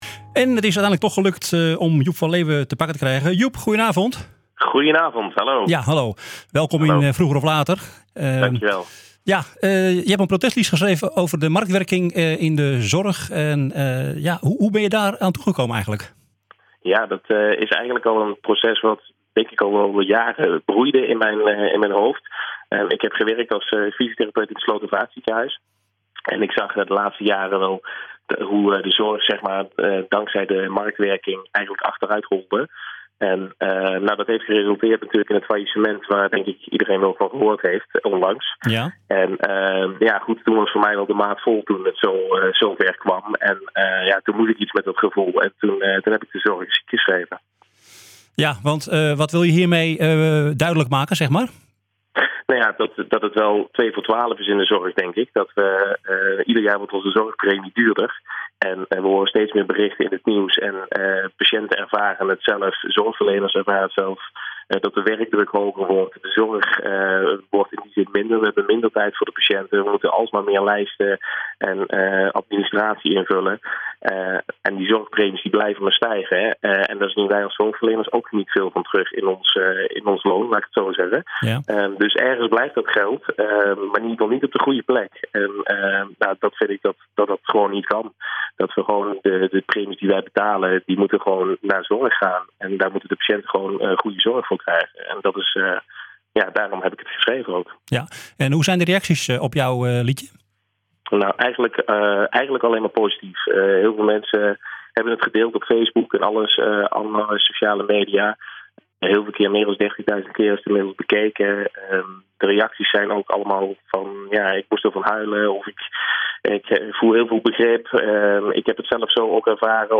Vroeger of Later - Protestlied tegen marktwerking in de zorg